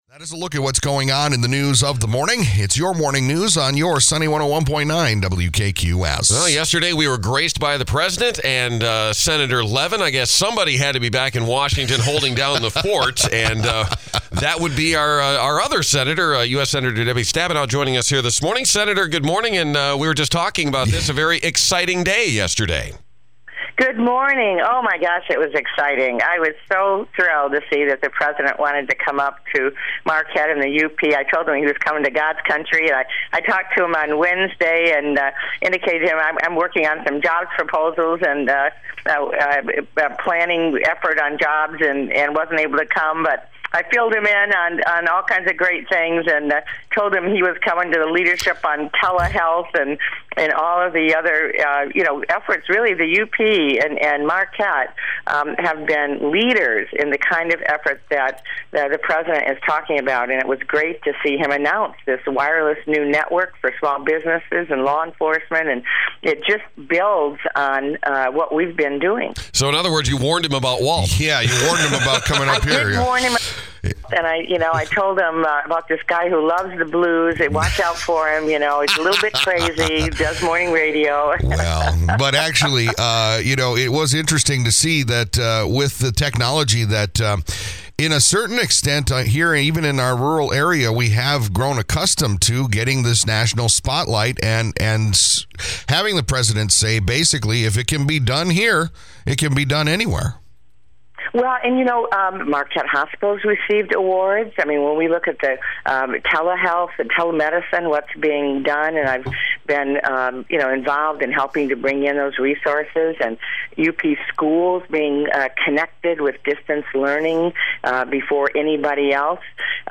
U-S Senator Debbie Stabenow called in this morning